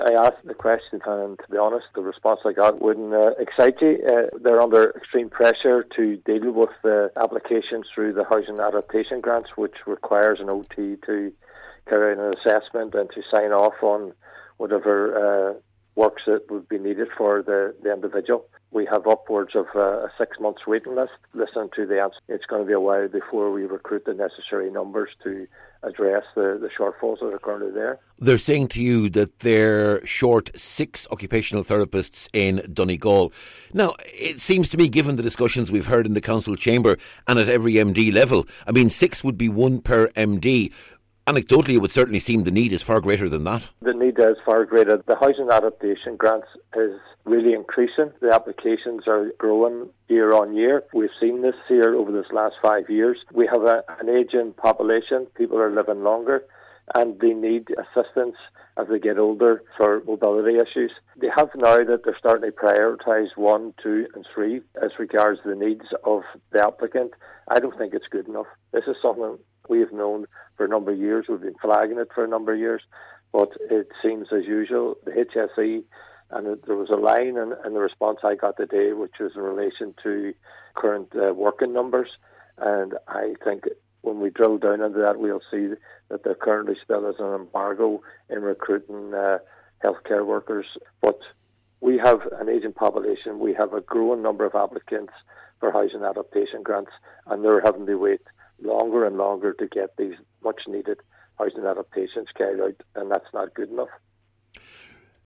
Councillor McMonagle however, says the HSE has been aware of the issue for some time: